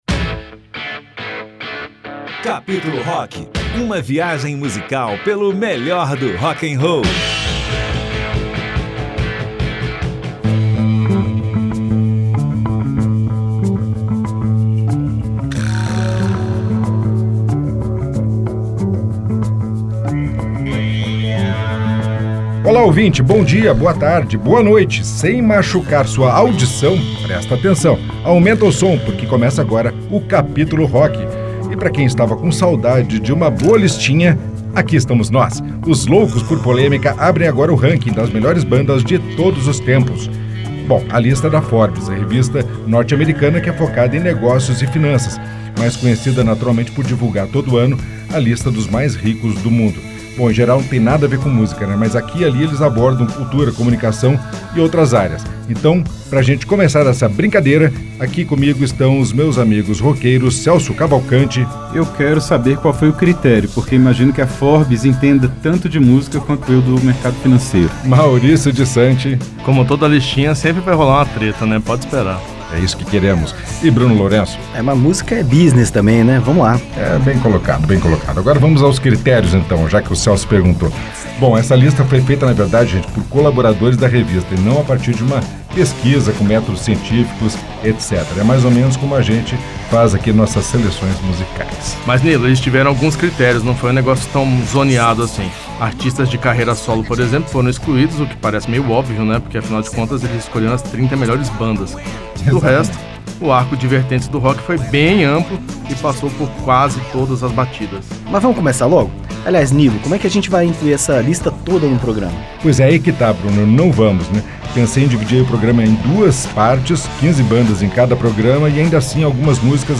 Os integrantes do programa se debruçaram sobre a relação das 30 maiores bandas de rock de todos os tempos, na opinião de colaboradores da revista no mundo todo. O resultado são aplausos e vaias dos roqueiros da Rádio Senado, e os melhores sons de cada banda.